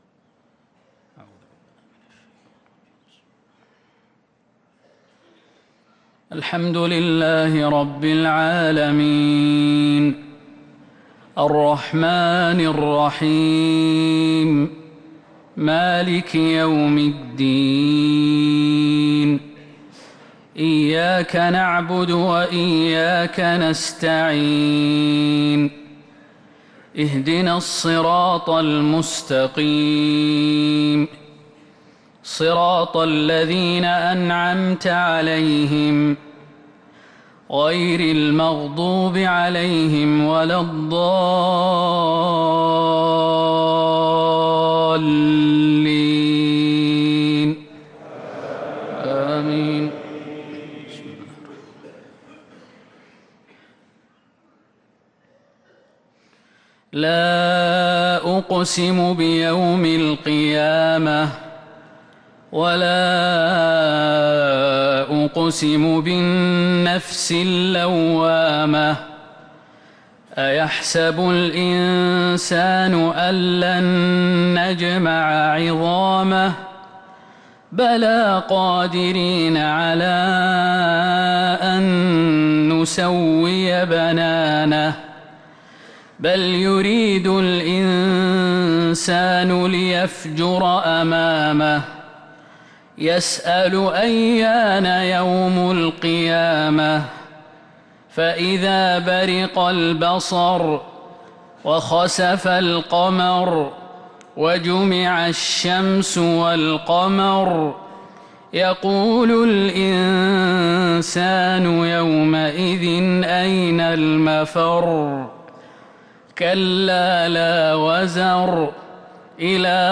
صلاة الفجر للقارئ خالد المهنا 26 ذو الحجة 1444 هـ